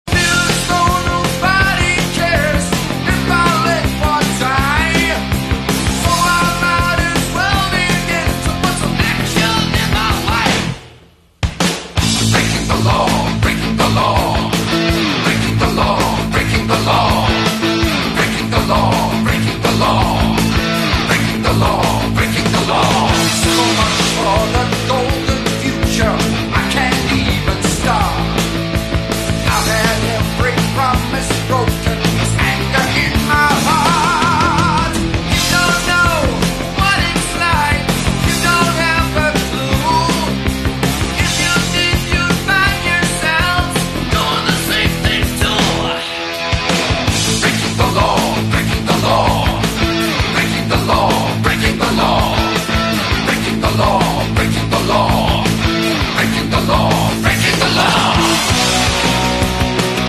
8 Bit Cover